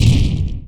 EXPLOSION_Short_Messy_stereo.wav